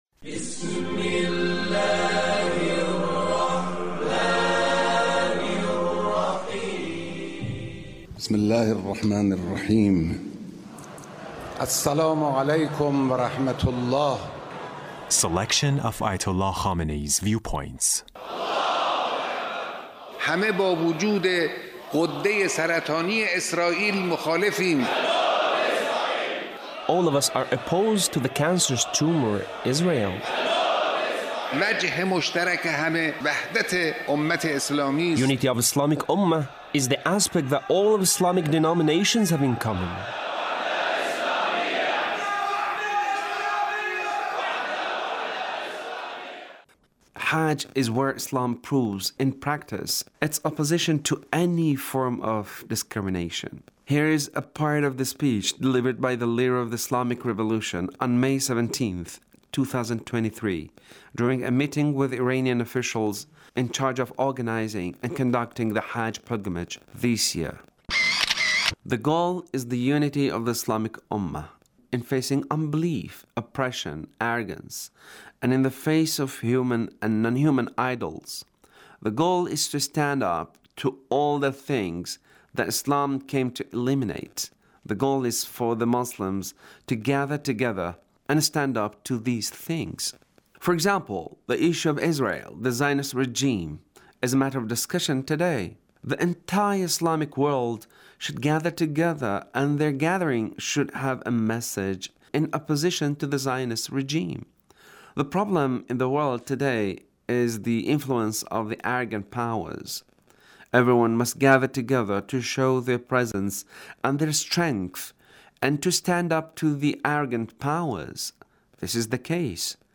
Leader's Speech in A Meeting with Iranian Commanders and Senior Military Officials